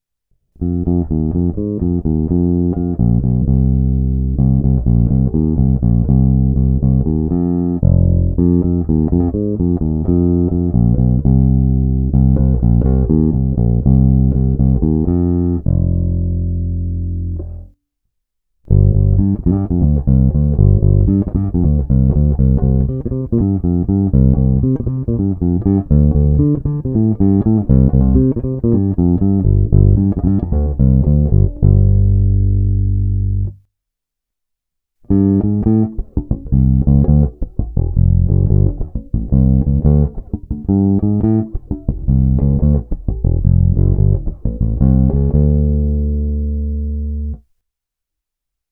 Úplným stažením tónové clony dosáhnete stále dosti vrčivého témbru.
Ukázka s úplně staženou tónovou clonou (hráno nad snímačem)